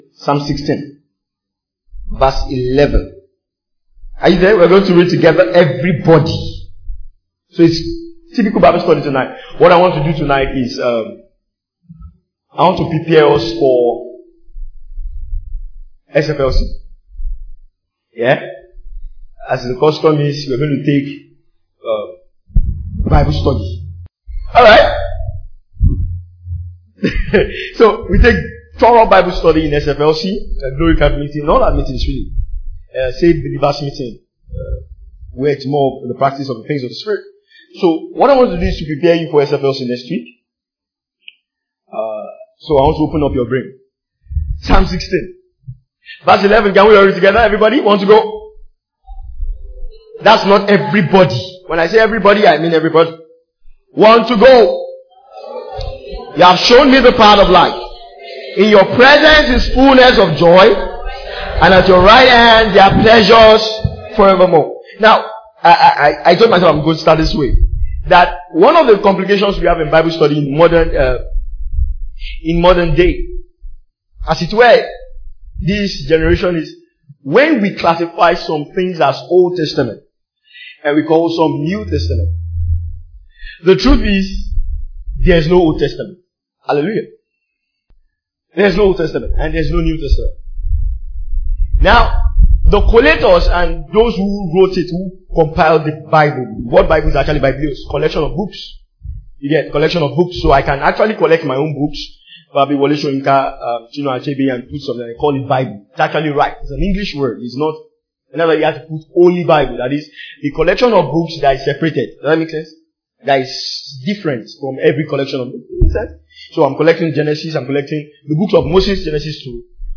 This teaching explains David’s Psalm 16 in light of Christ – What ‘His presence’ is and why it is important to our Christian faith. It’s bible study, it’s edification.